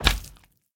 slime_big1.ogg